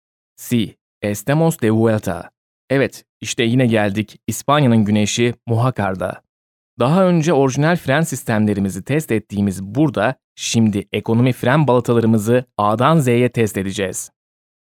Sprechprobe: eLearning (Muttersprache):